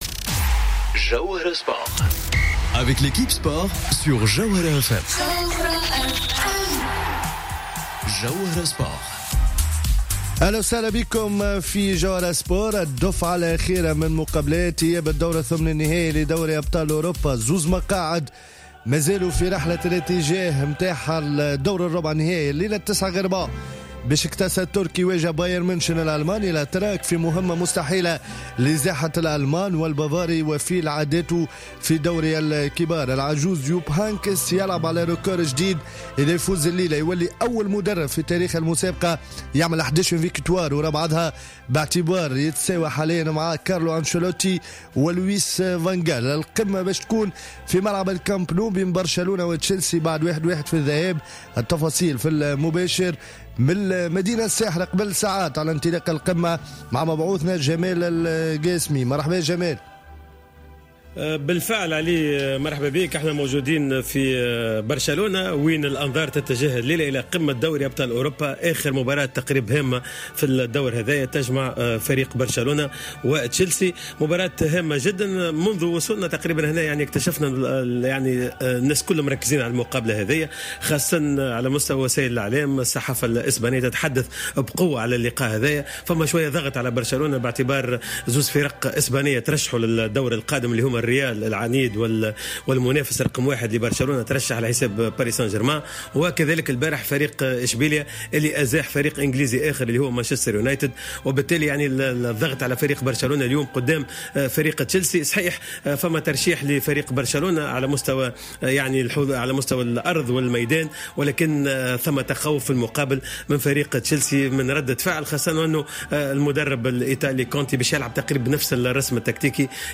مواكبة مباشرة من برشلونة للقاء القمة بين برشلونة و تشيلسي في رابطة أبطال أوروبا